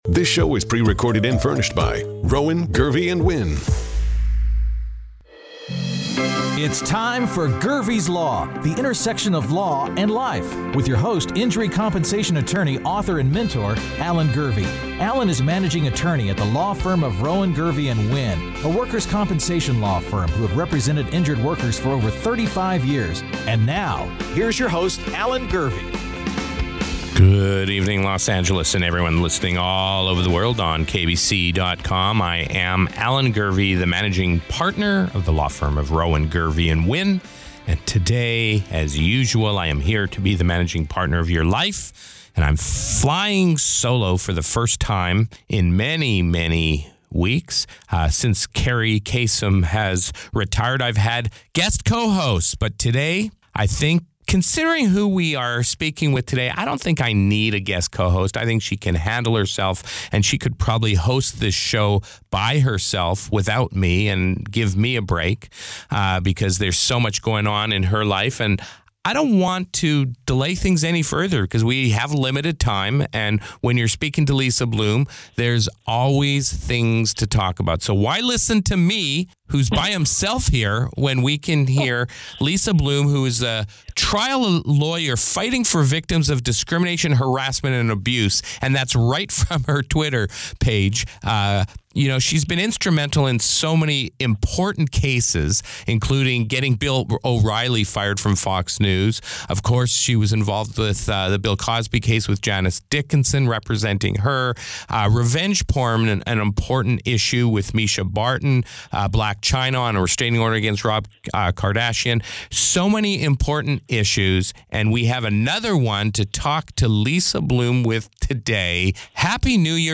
Here is the latest Gurvey's Law podcast, which originally aired on January 17th on KABC Talkradio.